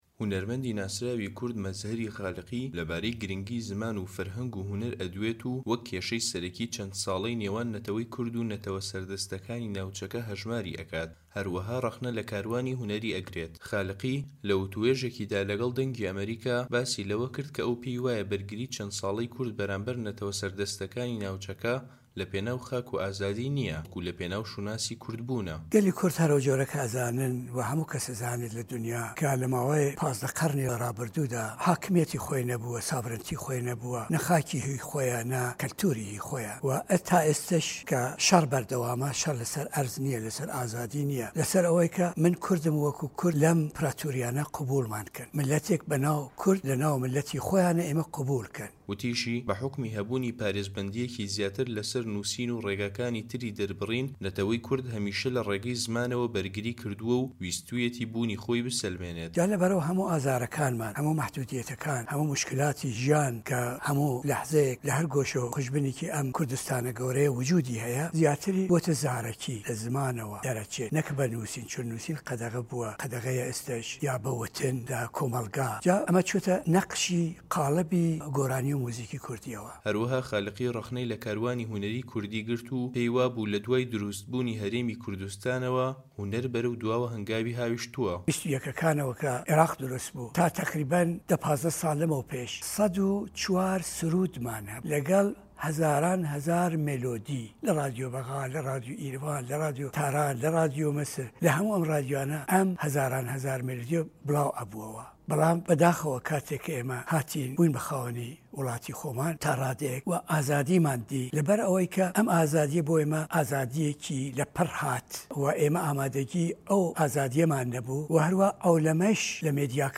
وتووێژ لەگەڵ مەزهەری خالقی